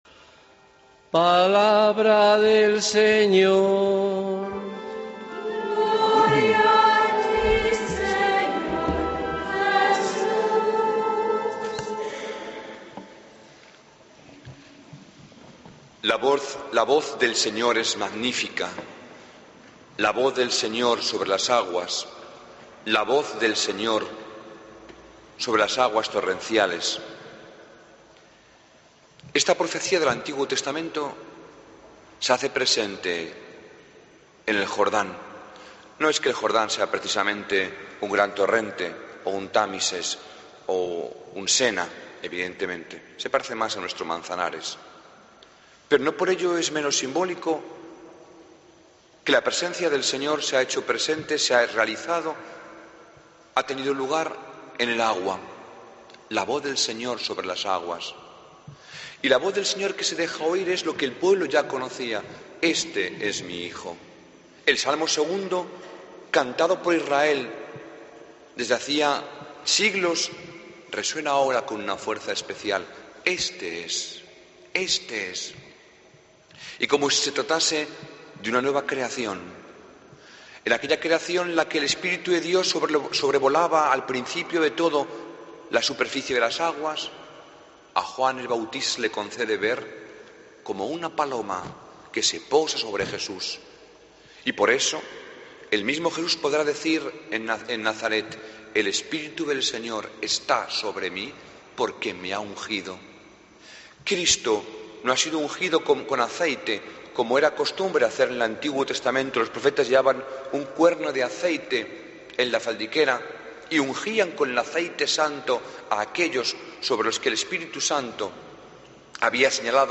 Homilía del domigo 13 de enero de 2013